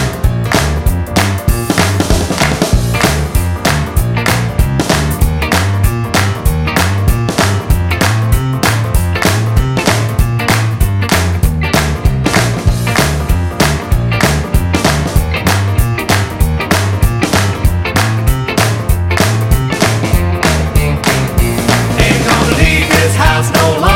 No Lead Guitar Pop (1980s) 3:00 Buy £1.50